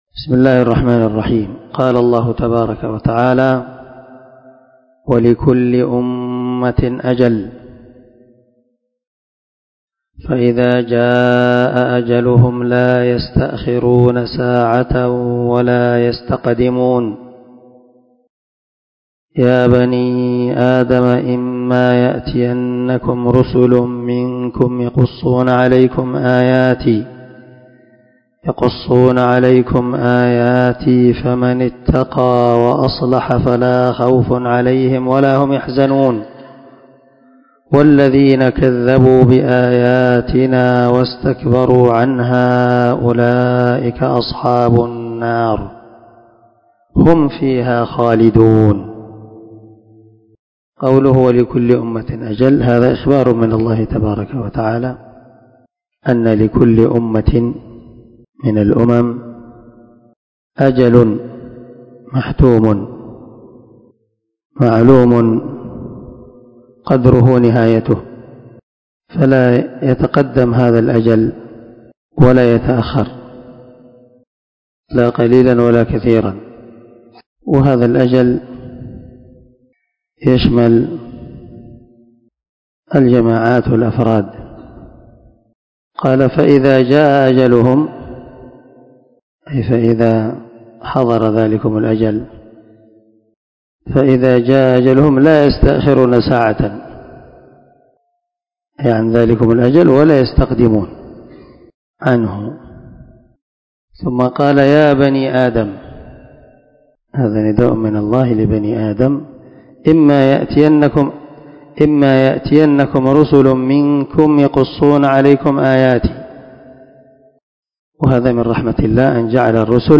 ✒ دار الحديث- المَحاوِلة- الصبيحة.